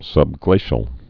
(sŭb-glāshəl)